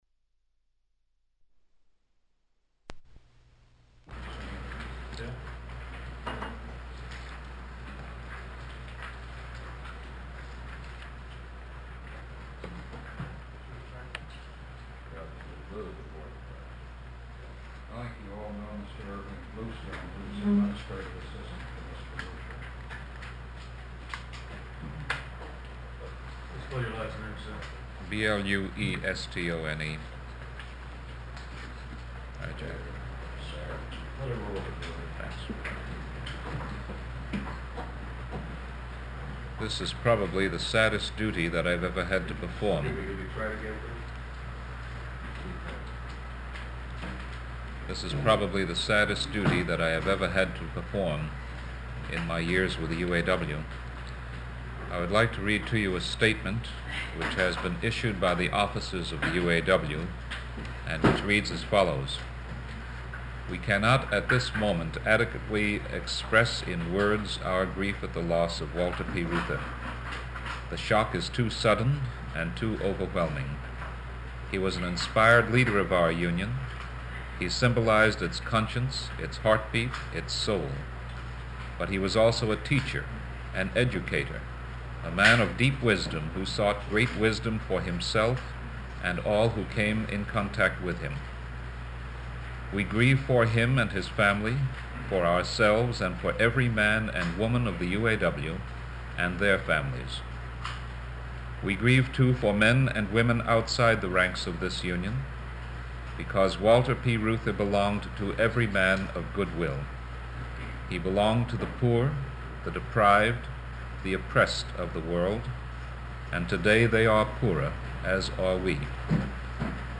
Press statement